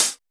909 CHH 2.wav